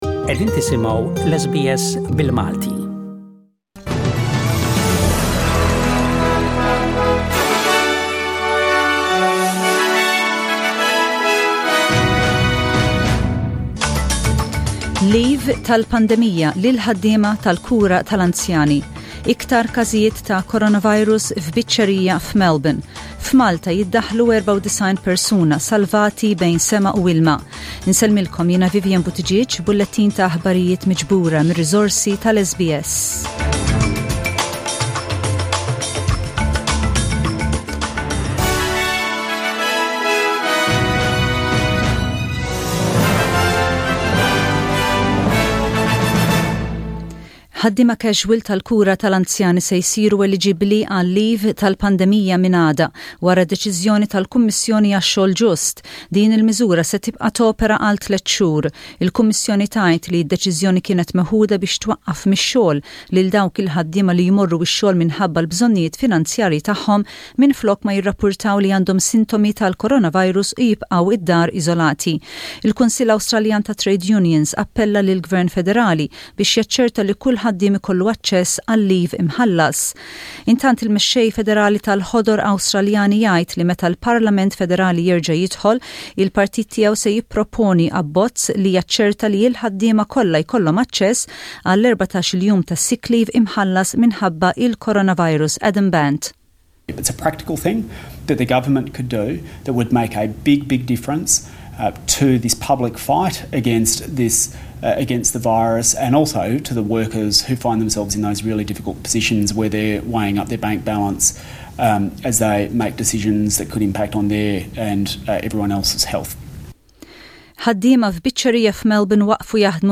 SBS Radio | News in Maltese: 28/07/20